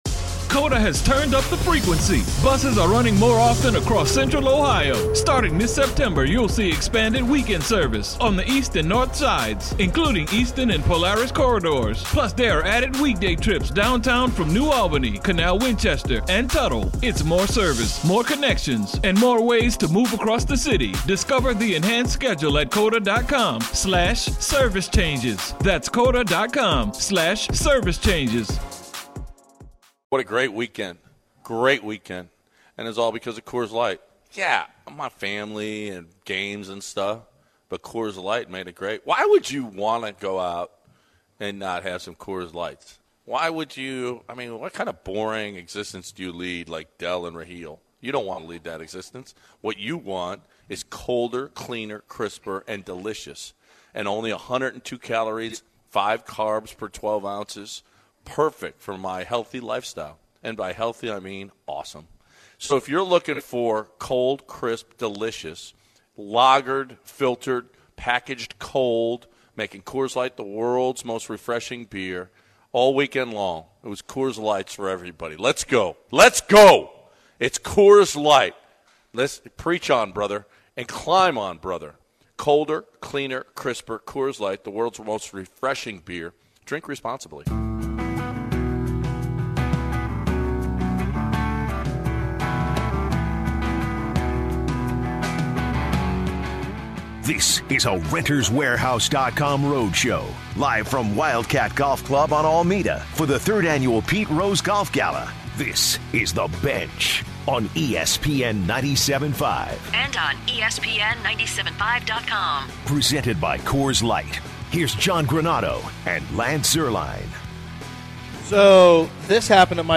on location at the Wildcat Golf Club